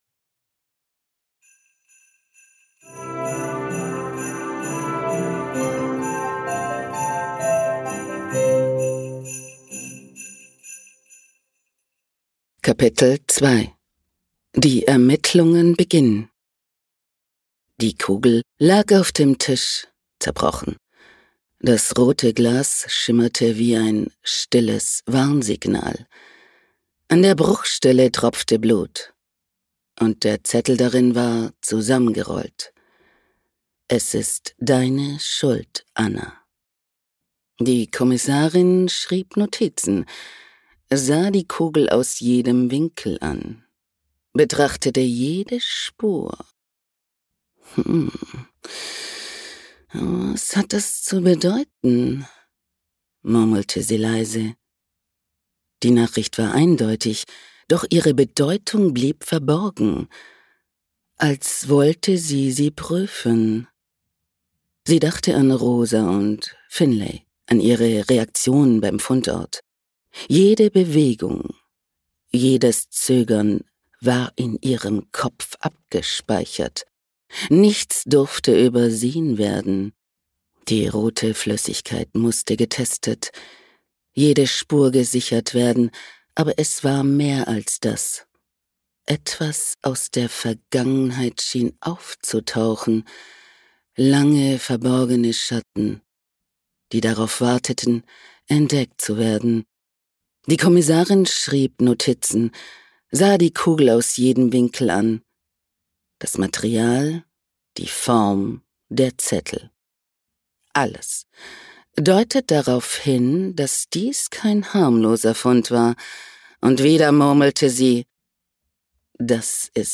Lass dich von acht verzaubernden Stimmen in die
„Das Geheimnis der Glaskugel" ist eine Advents-Kriminalgeschichte,